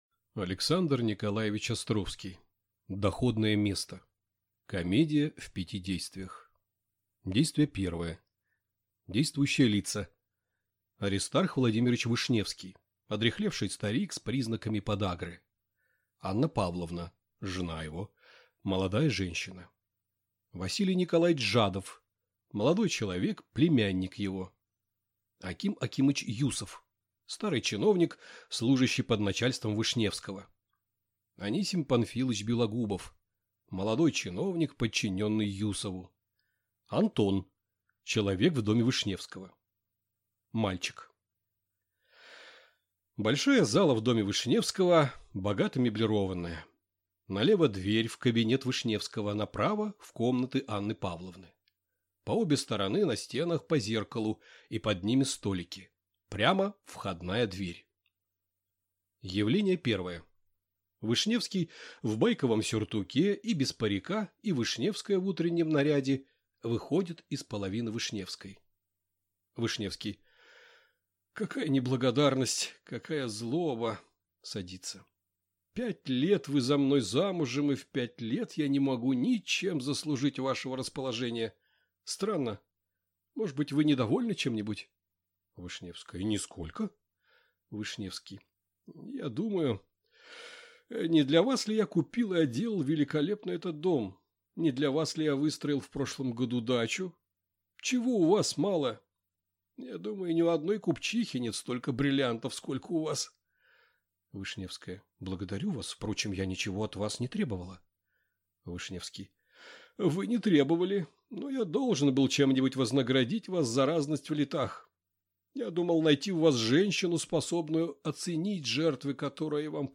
Аудиокнига Доходное место | Библиотека аудиокниг